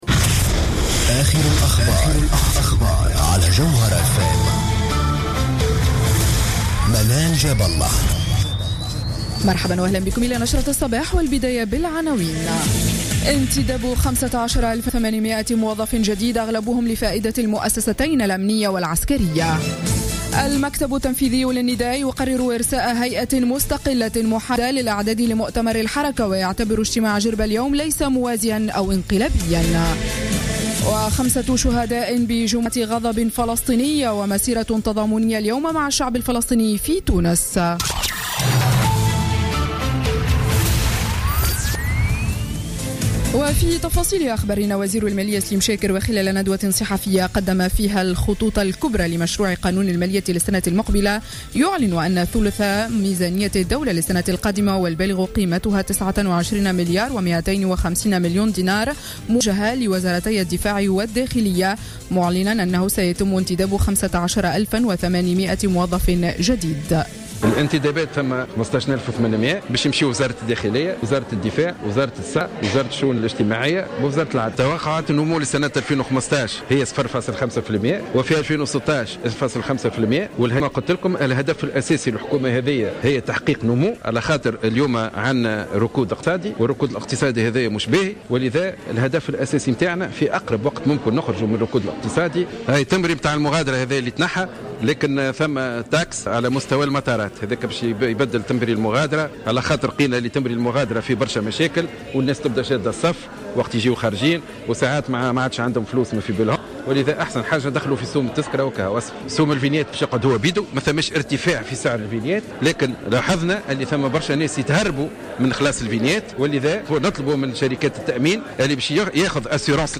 نشرة أخبار السابعة صباحا ليوم السبت 17 أكتوبر 2015